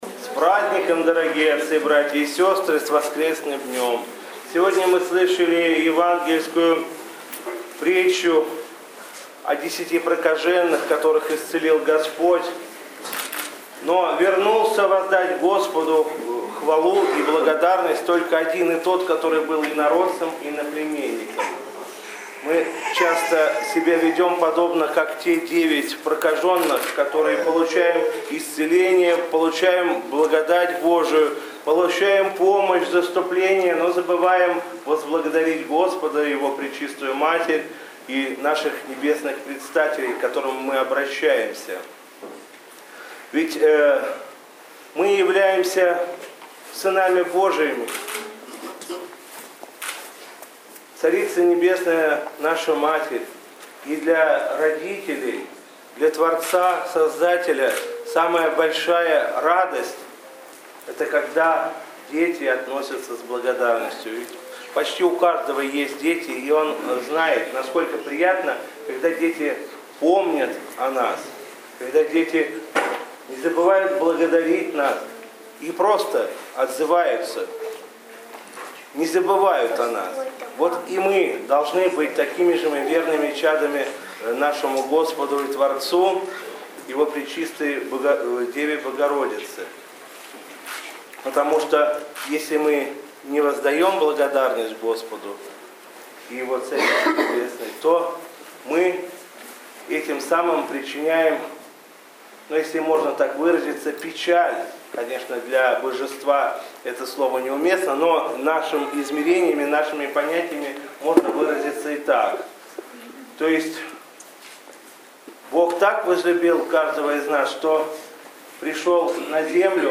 По окончании богослужения митрополит Игнатий обратился с архипастырским словом к присутствующим